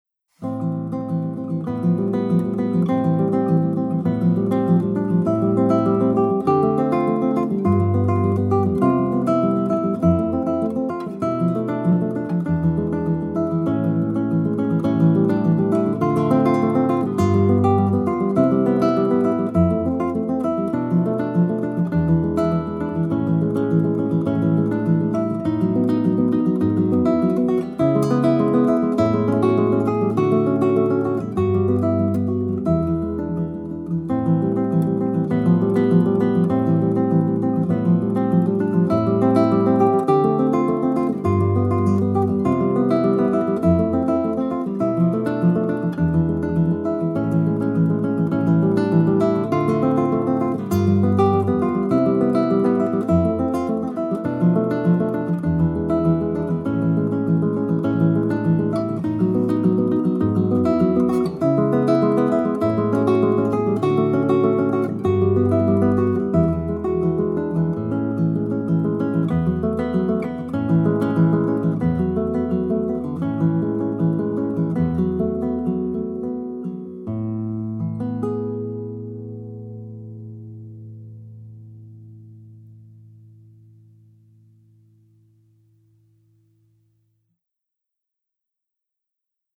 Gitarre